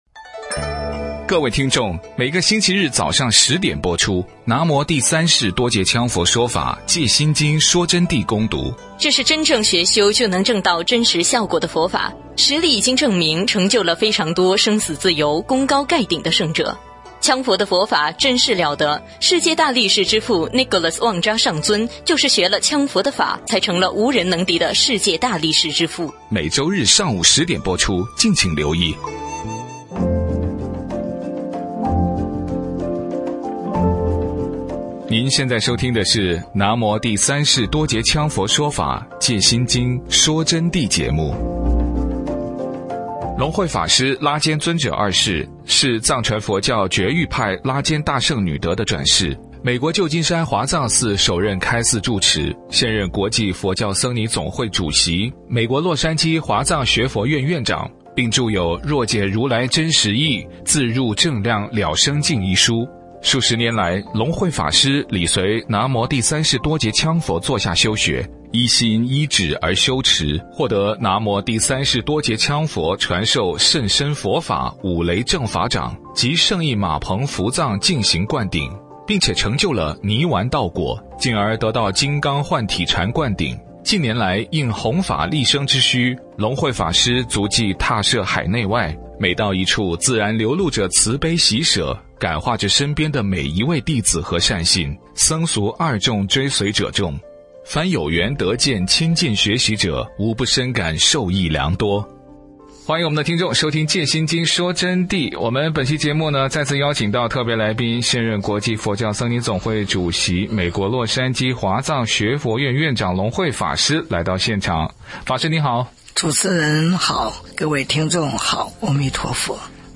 佛弟子访谈（三十七）浅谈因果的概念与真正的「胜义浴佛法会」现场实况